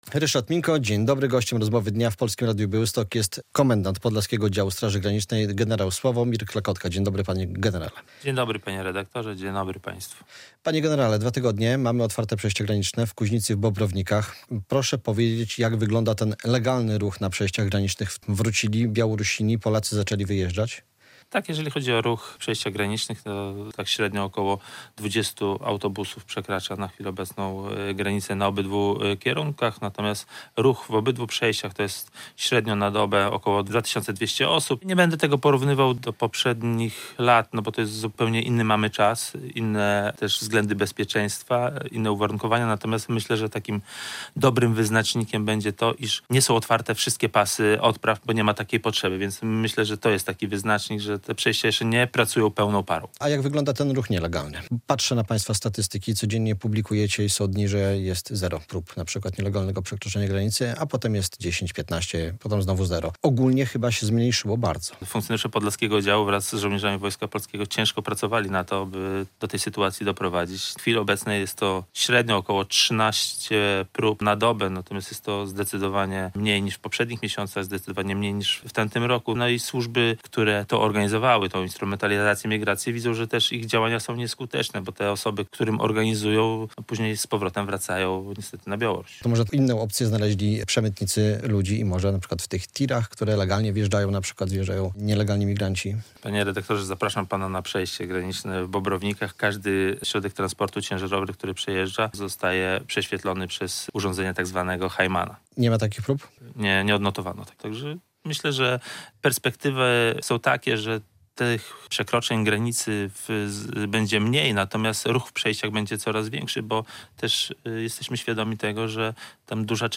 - Bardzo się cieszę, że przejścia zostały uruchomione, ale wciąż działamy na całej długości granicy tak, by sytuacja była stabilna i przewidywalna - mówi komendant Podlaskiego Oddziału Straży Granicznej gen. Sławomir Klekotka.
Radio Białystok | Gość | Czy przejścia graniczne znów są zagrożone?